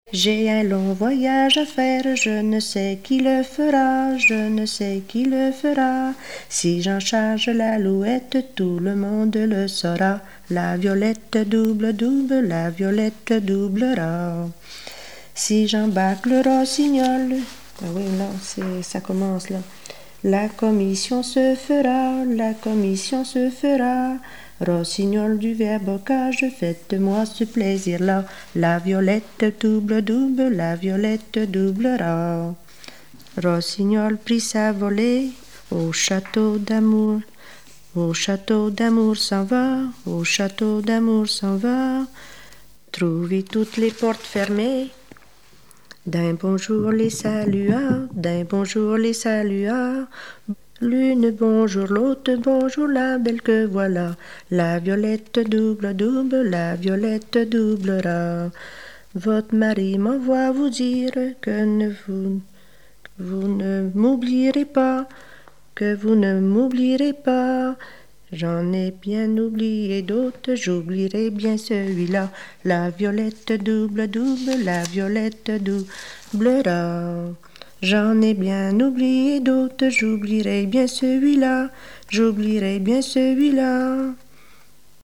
Mémoires et Patrimoines vivants - RaddO est une base de données d'archives iconographiques et sonores.
Recherche de chansons maritimes
Pièce musicale inédite